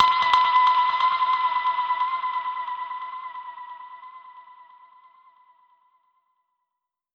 Index of /musicradar/dub-percussion-samples/134bpm
DPFX_PercHit_C_134-09.wav